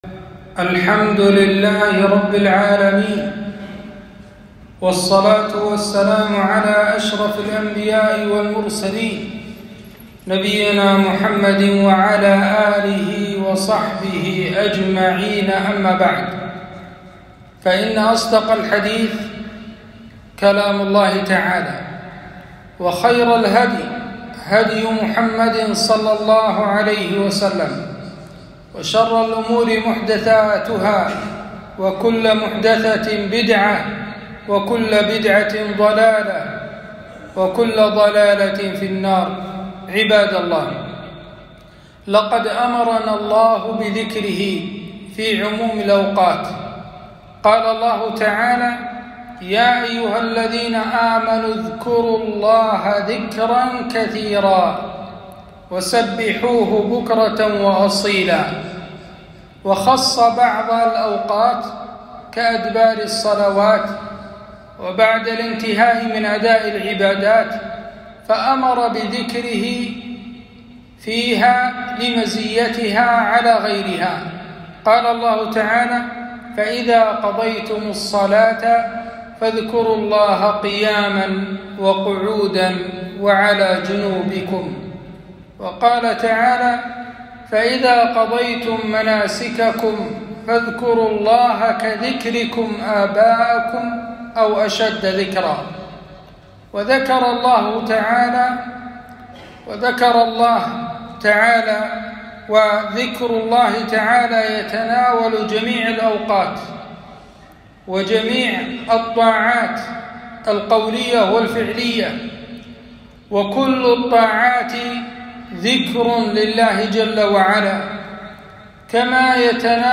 خطبة - أهمية التوحيد وخطورة الشرك